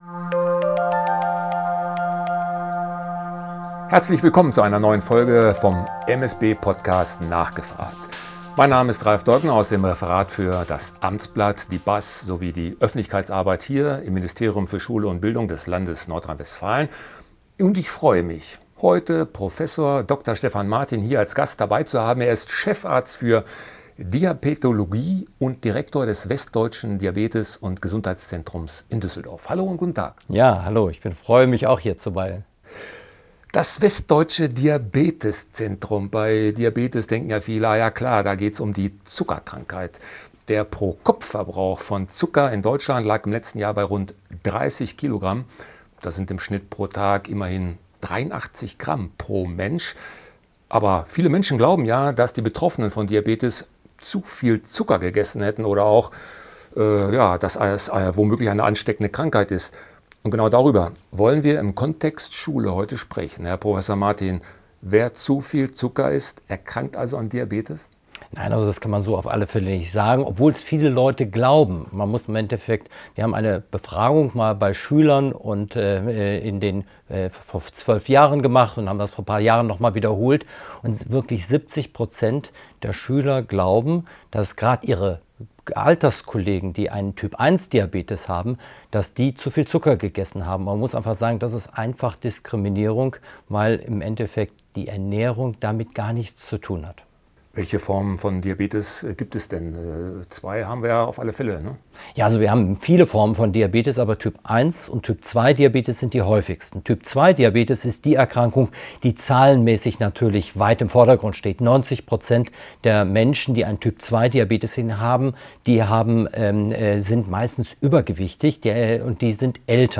Aufnahme am 14. Mai 2025 im Ministerium für Schule und Bildung NRW